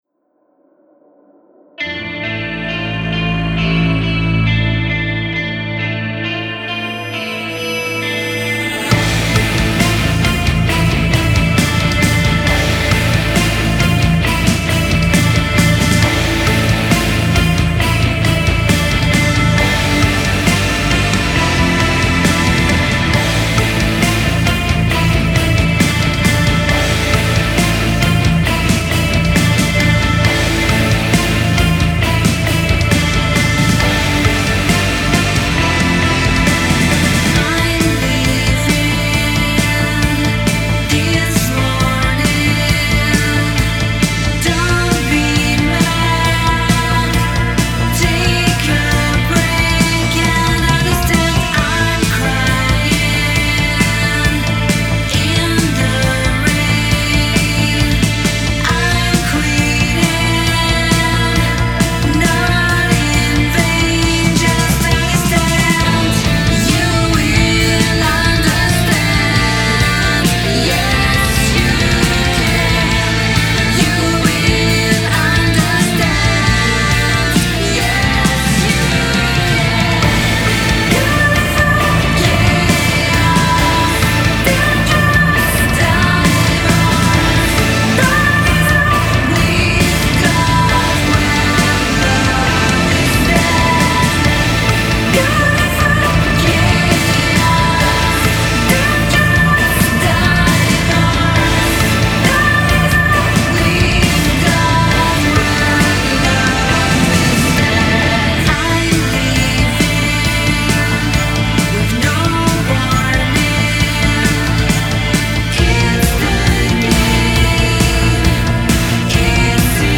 vocals
guitars and everything else